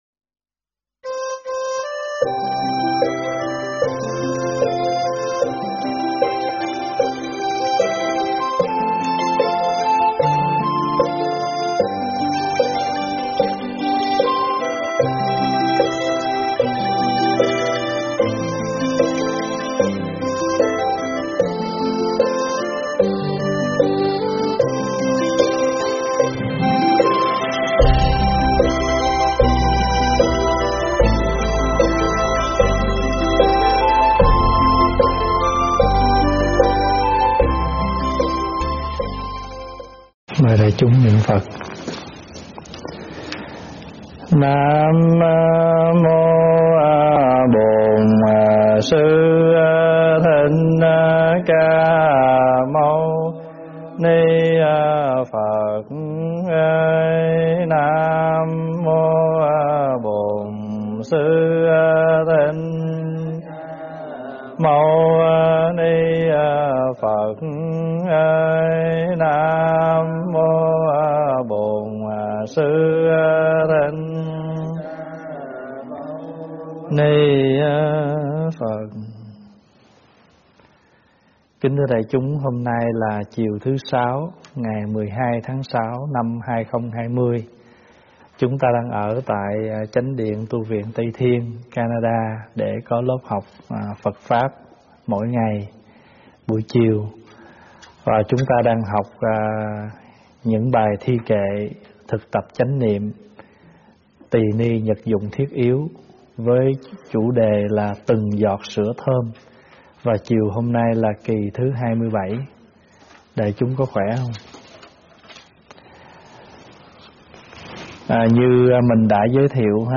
Nghe mp3 thuyết pháp Từng Giọt Sữa Thơm 27 - Kệ ngũ y
giảng tại Tv Trúc Lâm